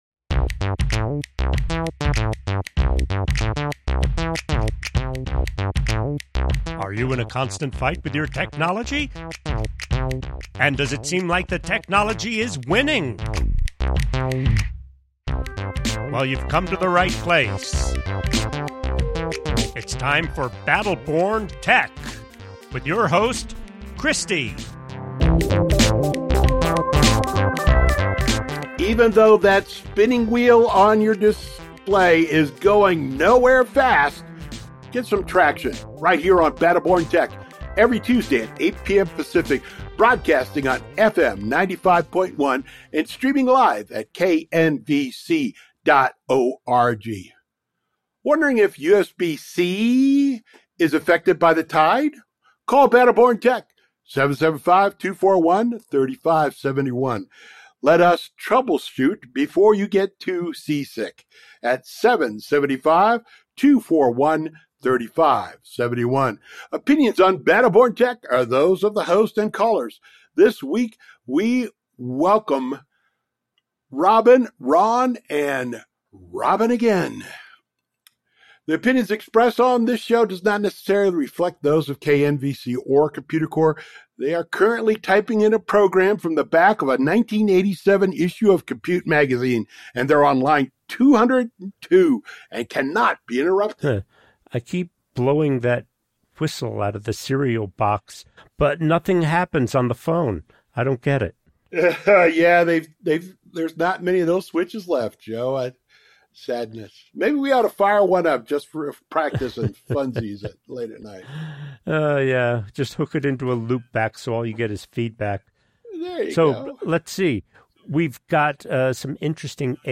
Battle Born Tech on KNVC 95.1 — real tech help from real callers, every Tuesday at 8 PM Pacific.